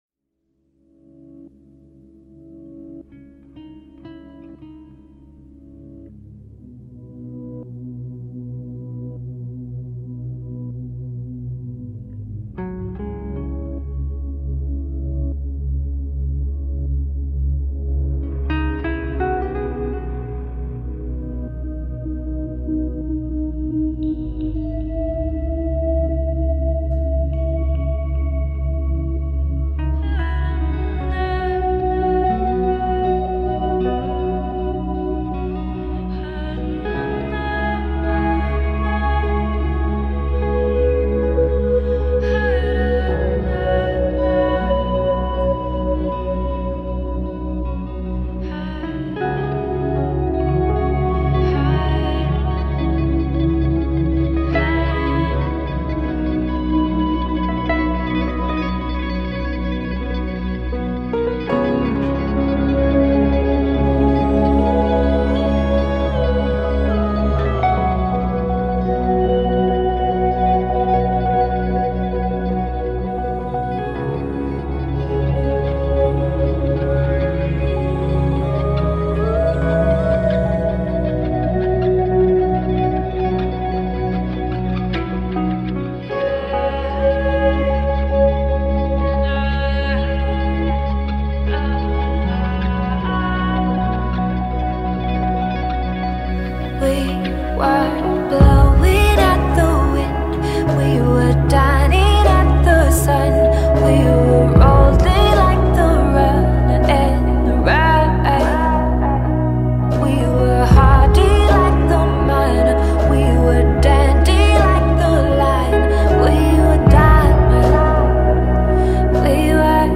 English band